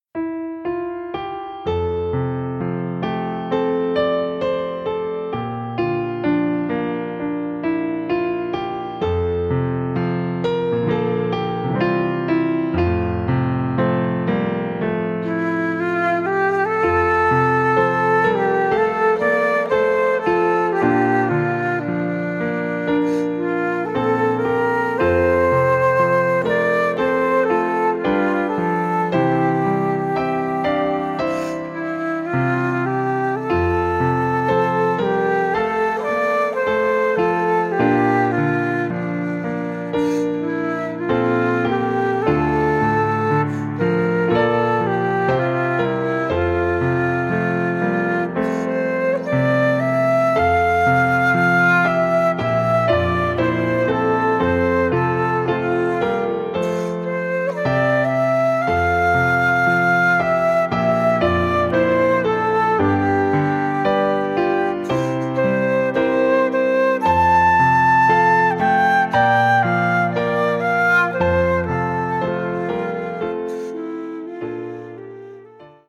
Música tradicional